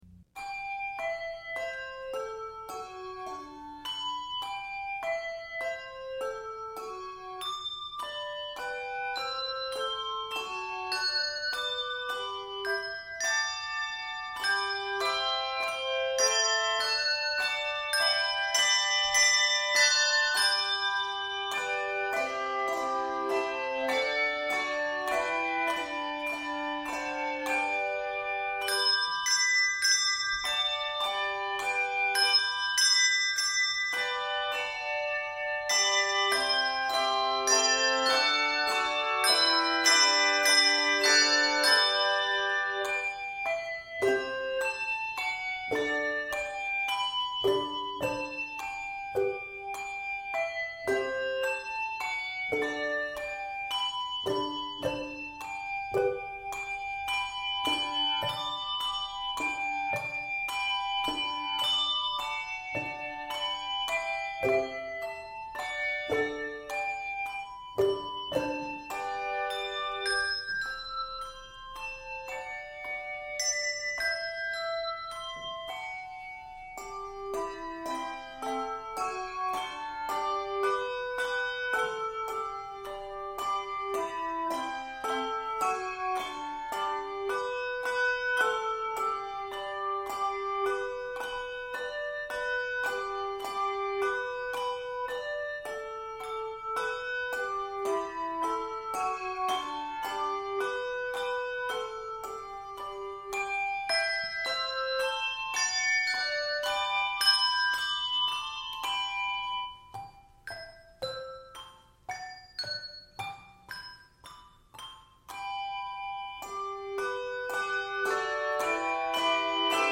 Simple and straightforward
Octaves: 3